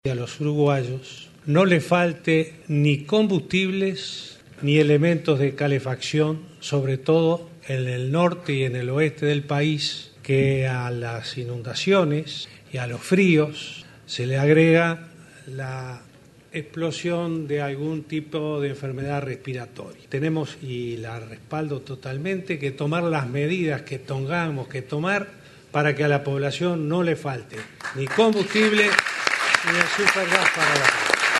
El presidente Tabaré Vázquez enfatizó que respaldará las medidas que tome el Ministerio de Industria para garantizar el abastecimiento de los combustibles y supergás a la población, frente a medidas sindicales de los funcionarios de Ancap. “Tomaremos las medidas que tengamos que tomar”, aseguró el mandatario al inicio del Consejo de Ministros abierto de este lunes 12 en el Cuartel de Blandengues de Montevideo.